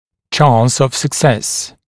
[ʧɑːns əv sək’ses][ча:нс ов сэк’сэс]шанс на успех